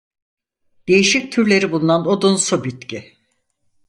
Read more wood-like (figuratively) rude, harsh, coarse woody Opposite of otsu, otsul Pronounced as (IPA) /o.dunˈsu/ Etymology From odun (“wood”) + -su (“-ish”).